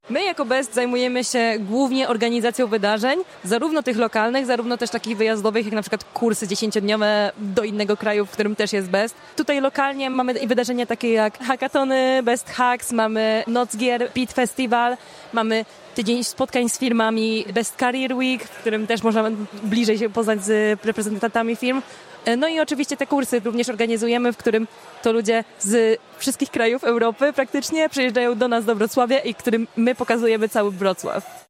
W środę, 29 października, wybraliśmy się na miejsce, żeby dać głos przedstawicielom kół naukowych.